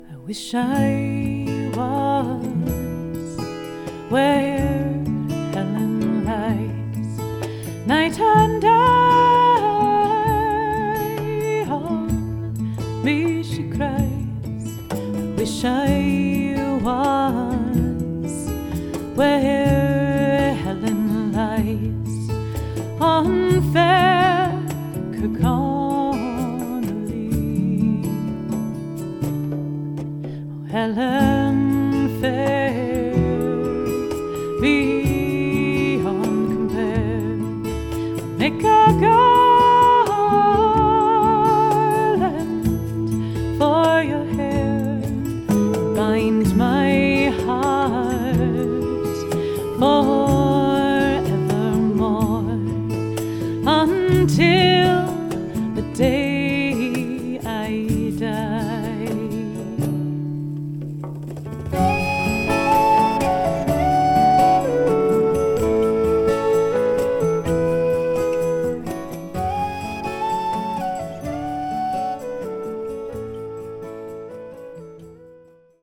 lyrical low whistle counter-melody